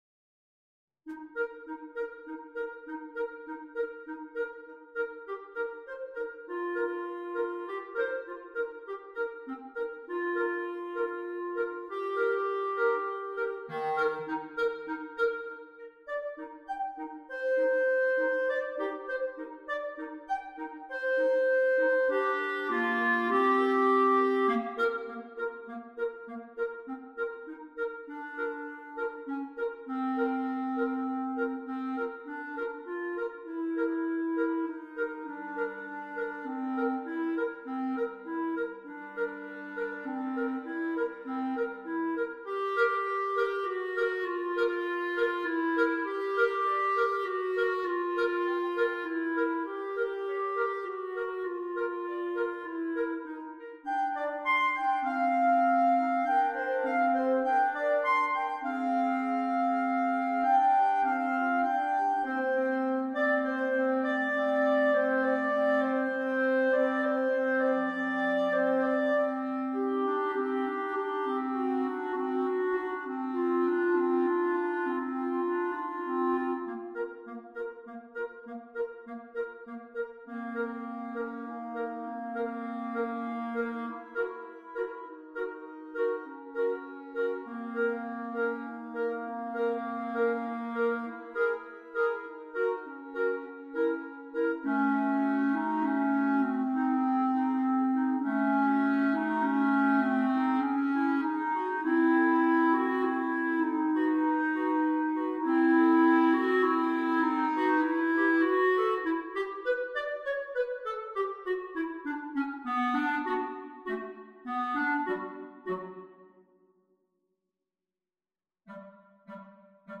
It suits the clarinet trio.
All 3 players play the melody.
Modern Classical